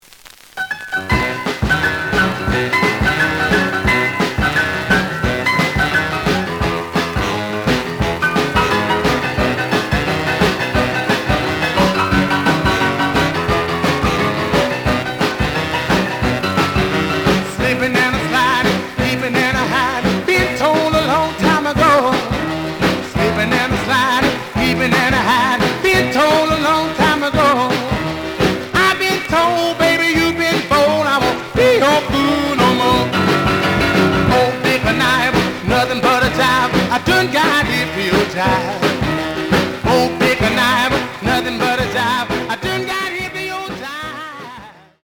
試聴は実際のレコードから録音しています。
●Genre: Rhythm And Blues / Rock 'n' Roll
G+, G → 非常に悪い。ノイズが多い。